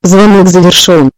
call_end.mp3